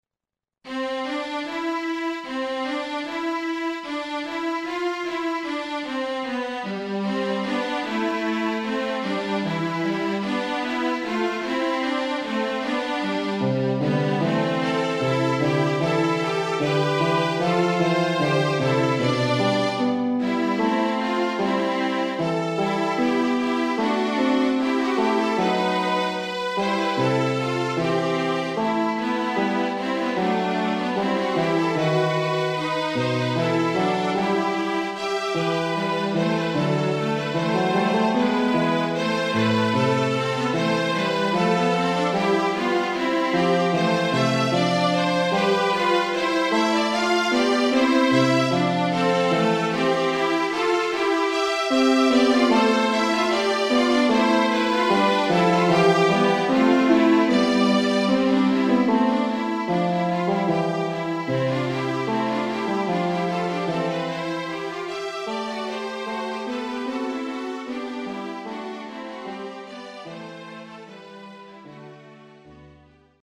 for  Trio, ATB or TrTB